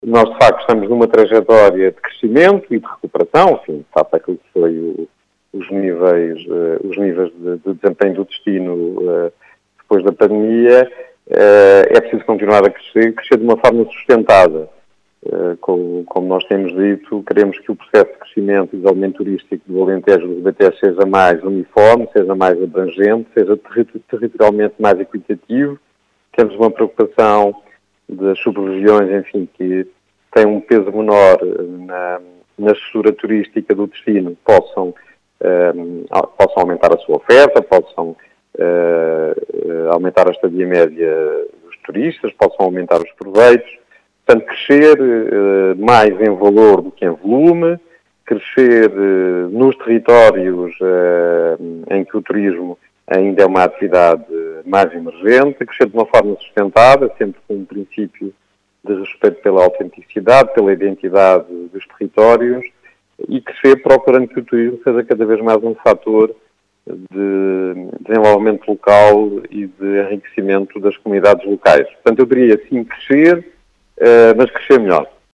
Em declarações à Rádio Vidigueira, o novo presidente da Entidade Regional de Turismo do Alentejo e Ribatejo, José Manuel Santos, diz querer manter o turismo a crescer na região, mas a “crescer melhor”.